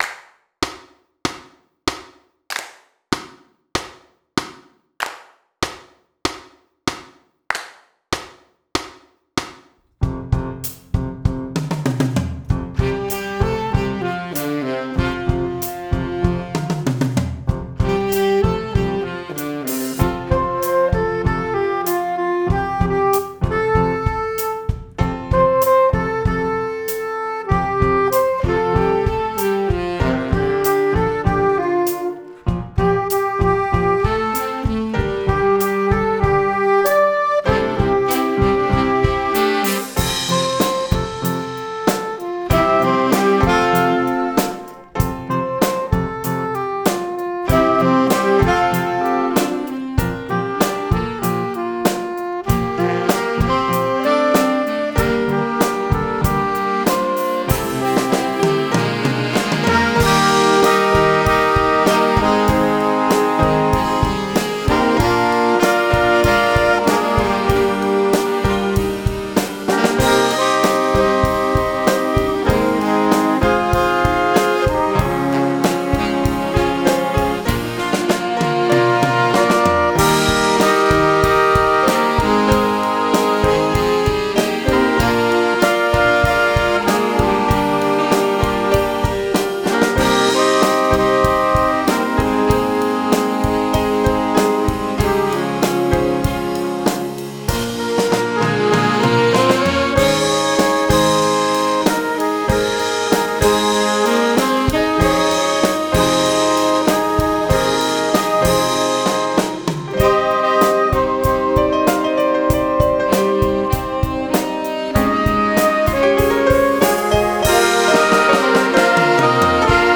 ohne Bass Weiterlesen »